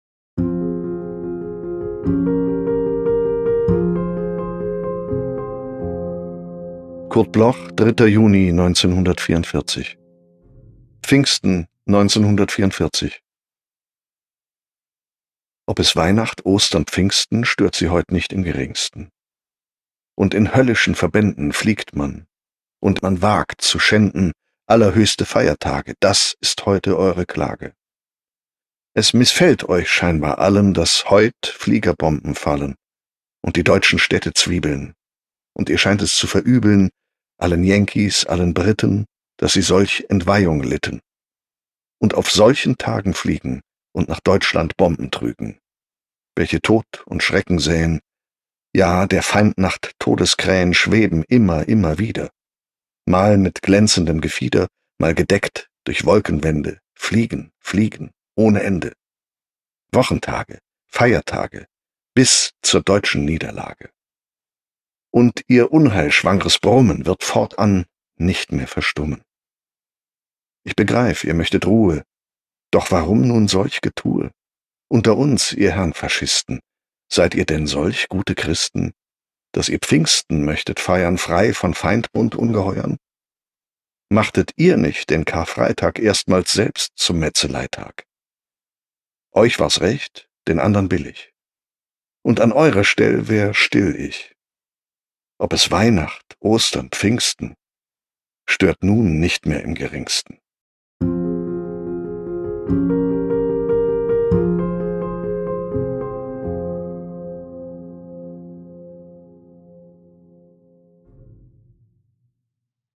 M.Brandt-Pfingsten-1944_NEU_mit-Musik_raw.m4a